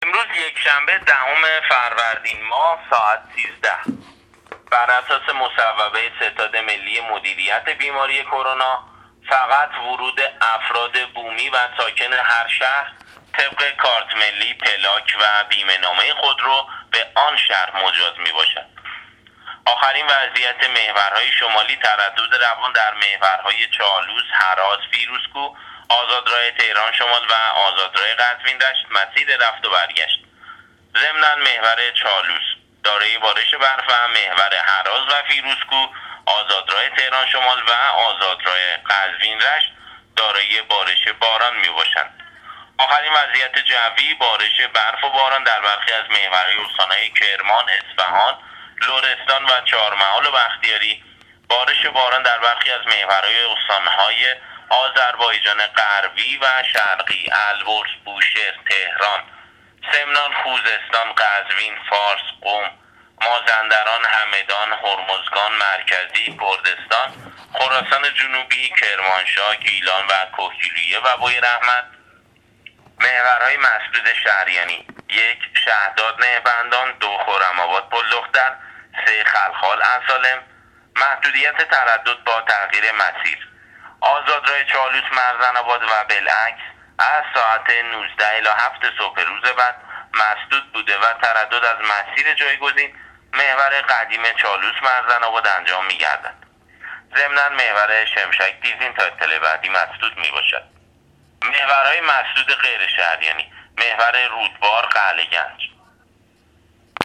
گزارش رادیو اینترنتی از آخرین وضعیت ترافیکی جاده‌ها تا ساعت ۱۳ دهم فروردین ۱۳۹۹